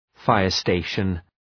Shkrimi fonetik{‘faıər,steıʃən}